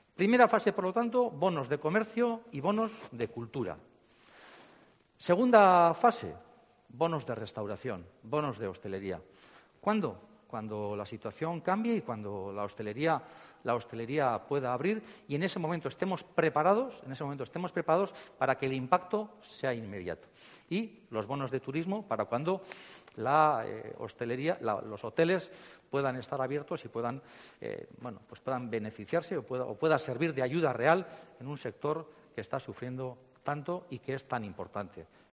Juan Mari Aburto, alcalde de Bilbao